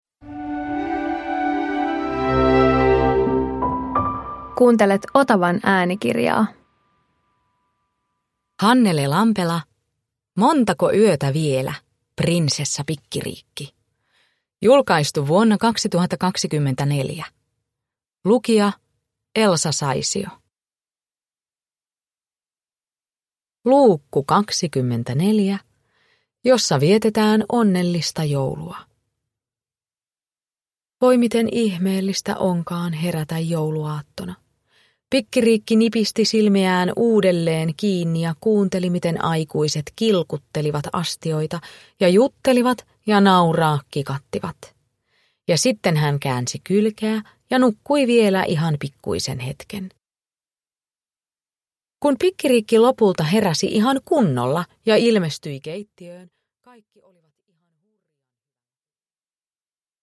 Montako yötä vielä, Prinsessa Pikkiriikki 24 – Ljudbok
Uppläsare: Elsa Saisio